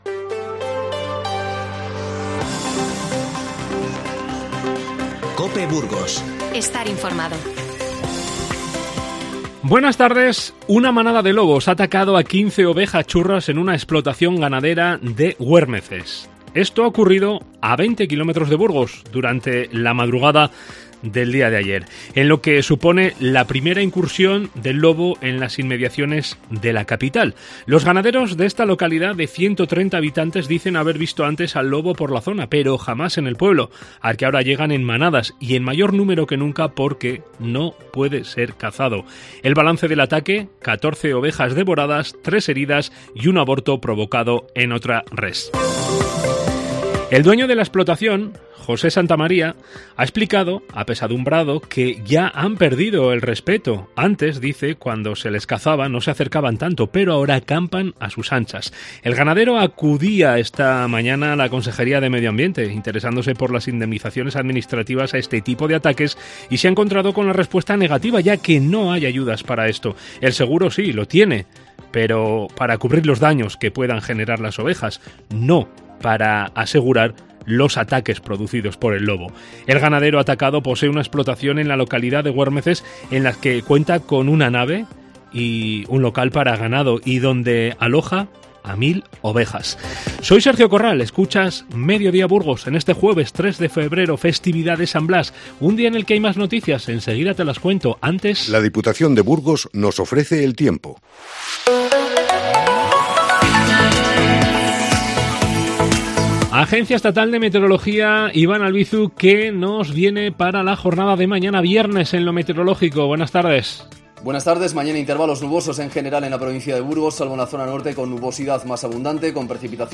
INFORMATIVO Mediodía 03-02-22